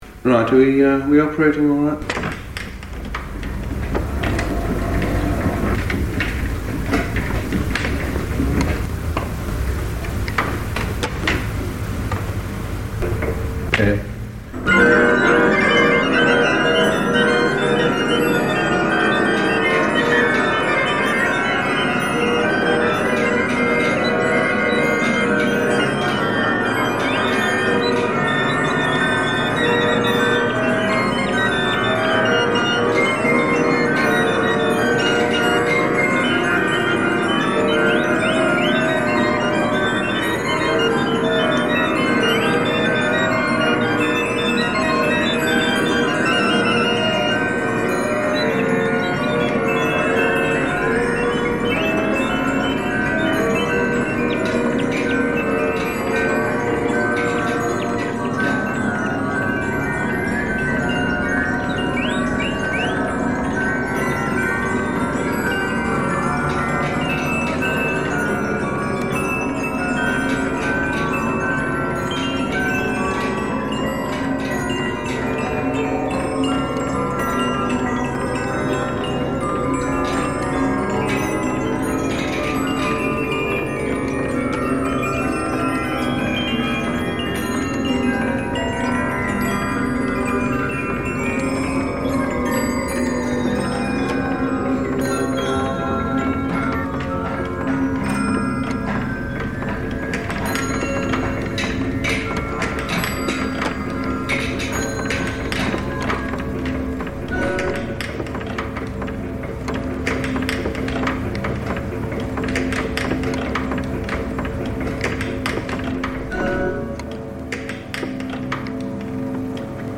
The devices he is tasked with recording served as elegant salon entertainment for social occasions, aided musical democratisation and were vessels for cherished memories, and carry a huge weight of human stories within them.
Wrangling a reel-to-reel tape deck and microphone, our archivist removes the collection from stasis one by one, noting their type, condition and the artisan or company that constructed them. His day is crowded by a rich litany of sounds - pieces of music punched on metal discs and cylinders, the creaking of wooden cases, reed combs, organ bellows, latches, hand-cranks, whistles, mechanical birds, traffic. He documents them phlegmatically in all their tinkling, jangling, hooting, chiming, clattering glory.